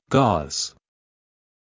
gauze はイギリス英語とアメリカ英語で発音が少し異なります。
【アメリカ英語】gauze /gɑːz/
gauze-US.mp3